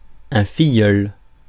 Ääntäminen
Ääntäminen France: IPA: /fi.jœl/ Haettu sana löytyi näillä lähdekielillä: ranska Käännös Substantiivit 1. кръщелница Suku: f .